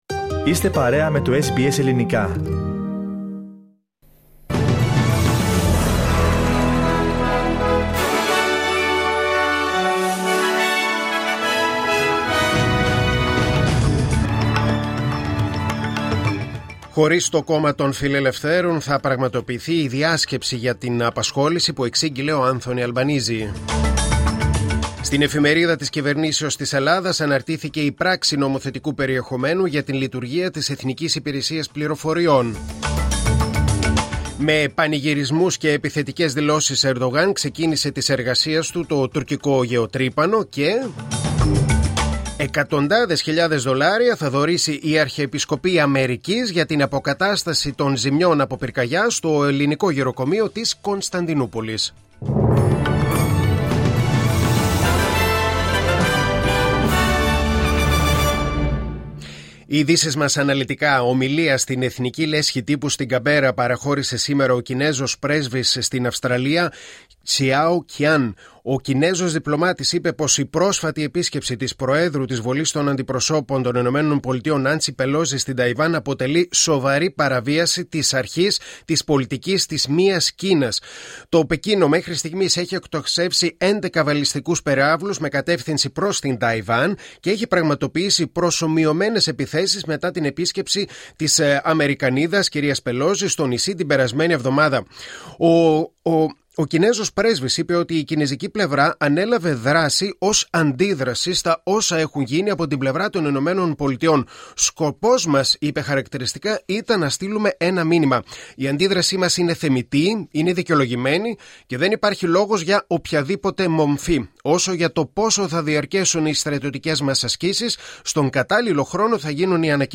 News in Greek: Wednesday 10.8.2022